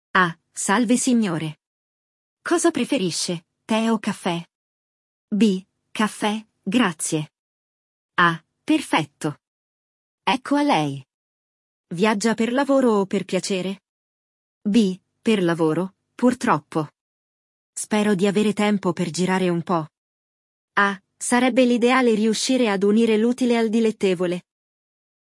Il dialogo – O diálogo